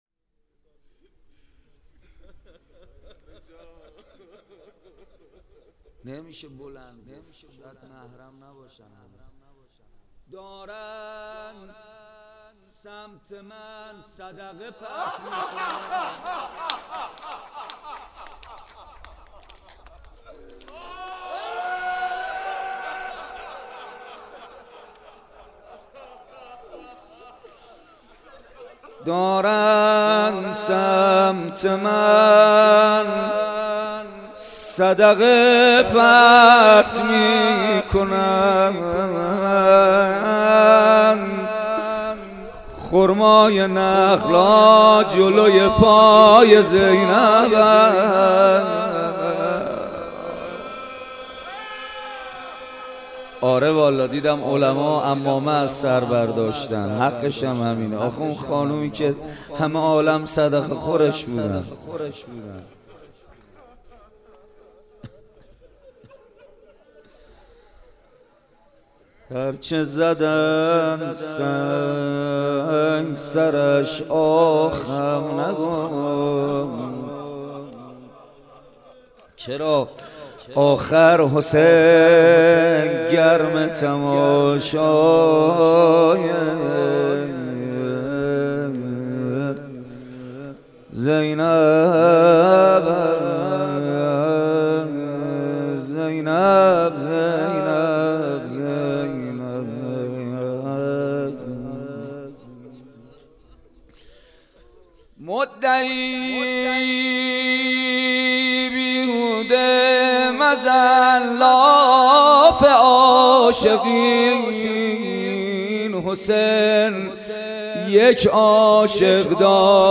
محرم 1399